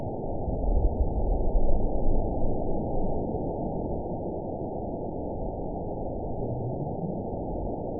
event 913785 date 04/20/22 time 11:48:00 GMT (3 years ago) score 9.49 location TSS-AB01 detected by nrw target species NRW annotations +NRW Spectrogram: Frequency (kHz) vs. Time (s) audio not available .wav